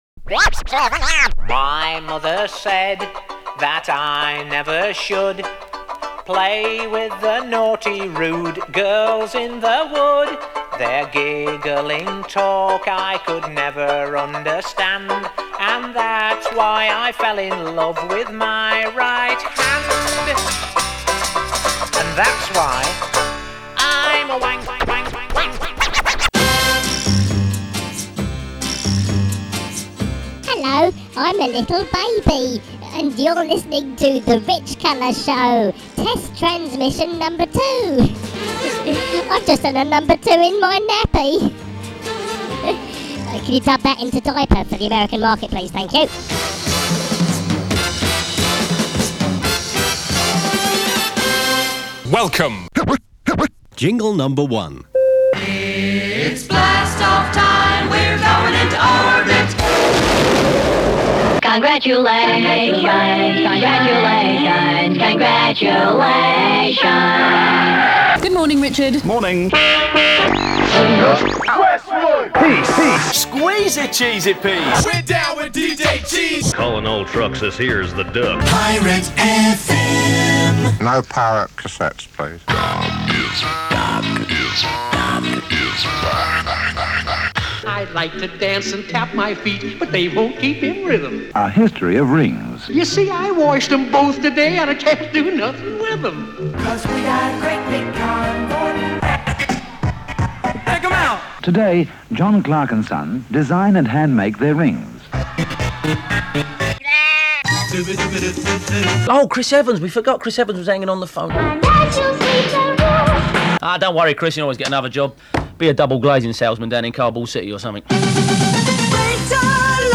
Here's another Richcolour Show test transmission, recorded in 1999.